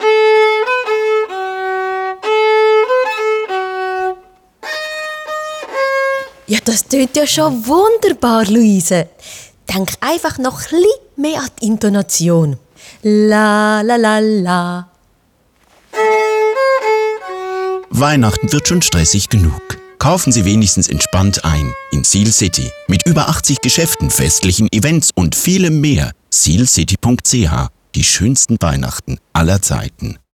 Neue Radiospots für das Einkaufszentrum Sihlcity.